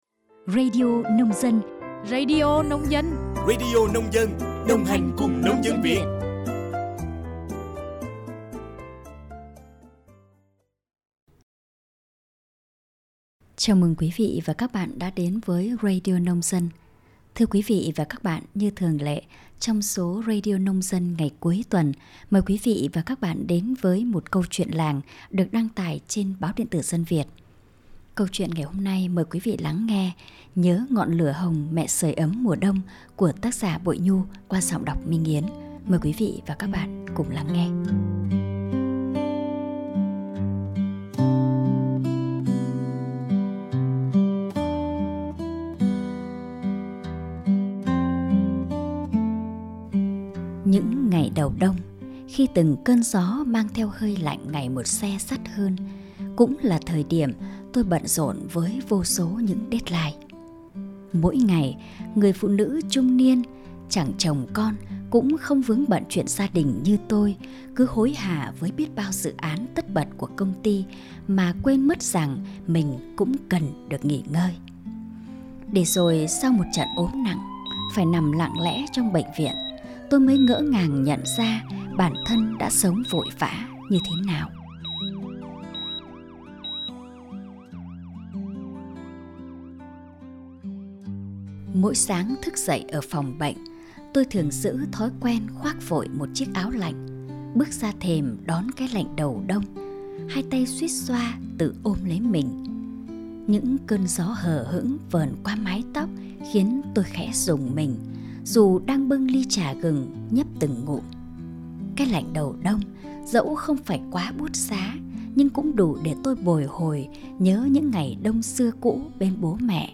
Radio online - Nhớ ngọn lửa hồng mẹ sưởi ấm mùa đông - ký ức làng - radio nông dân - radio truyện